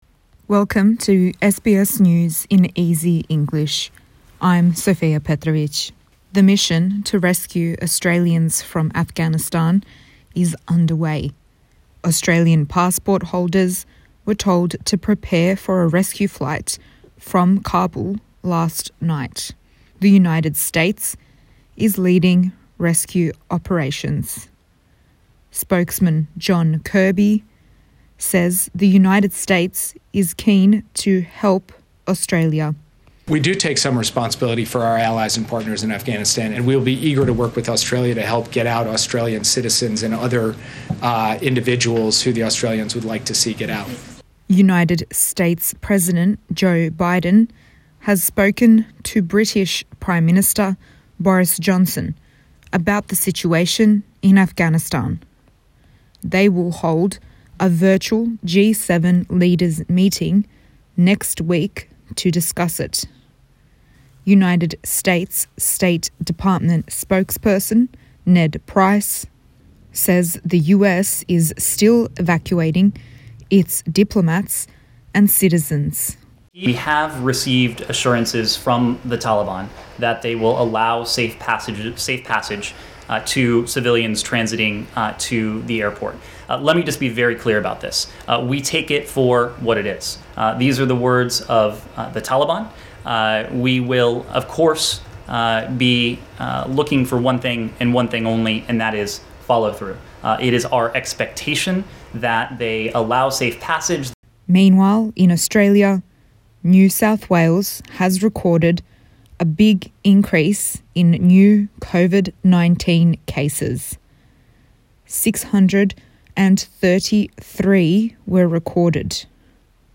A daily 5 minute news wrap for English learners.